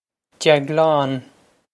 Deaglán Jag-lahn
This is an approximate phonetic pronunciation of the phrase.